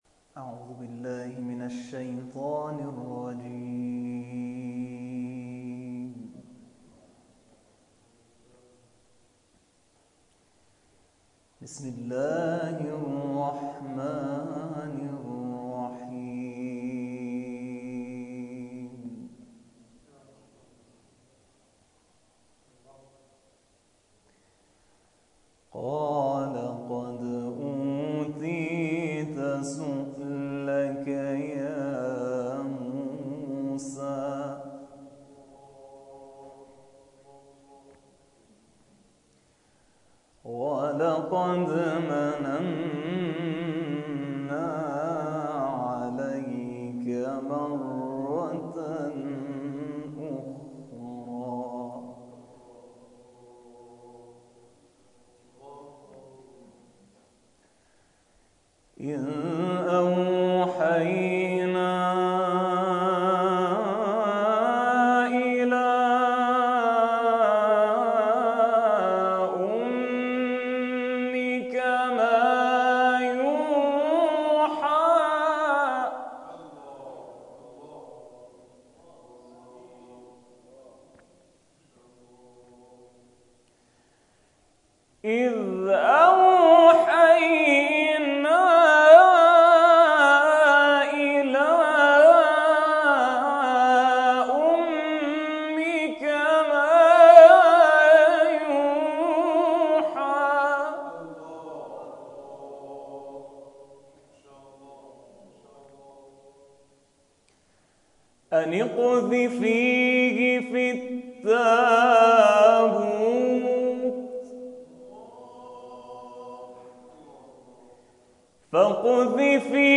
جلسه قرآنی در جوار «سیدالکریم» + صوت و عکس
این جلسه قرآن که در جوار حرم عبدالعظیم حسنی(ع) برگزار می‌شود، تلاوت‌هایش، حس و حال متفاوتی از جلسات دیگر دارد و به برکت حضرت عبدالعظیم(ع) فضایی معنوی ایجاد می‌شود.